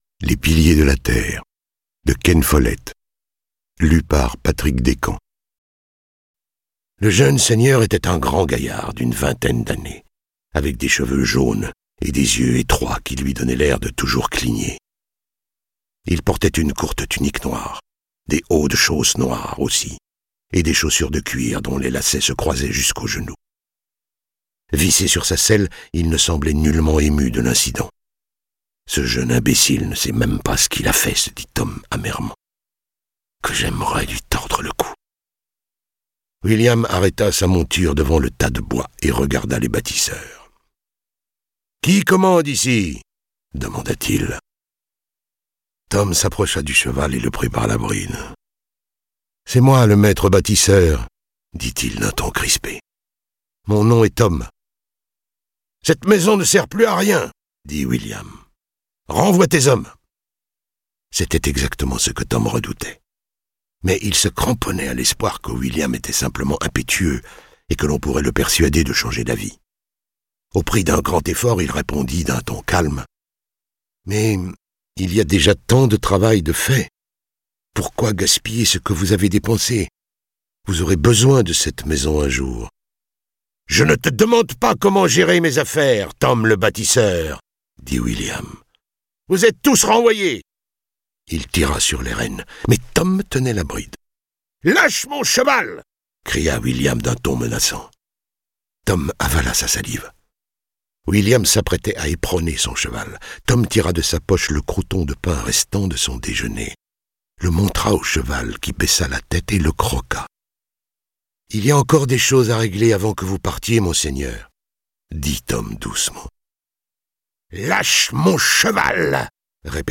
je découvre un extrait - Les Piliers de la terre 1 - Ellen de Ken Follett